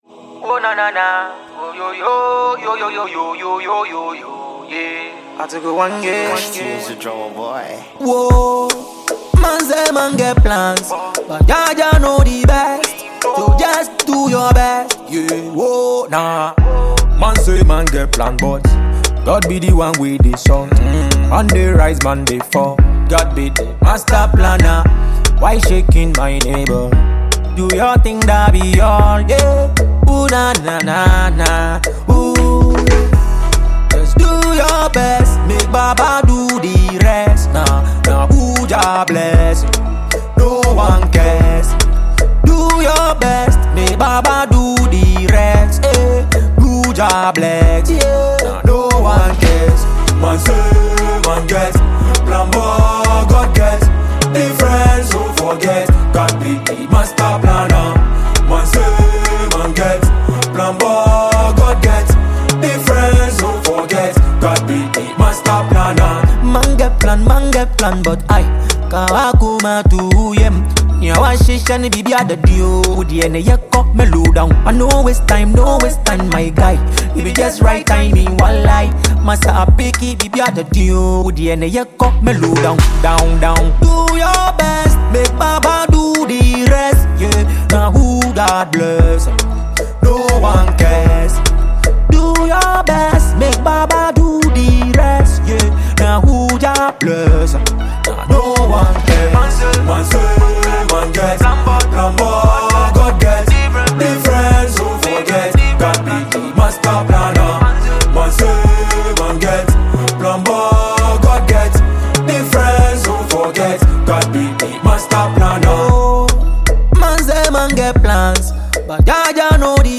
mid-tempo record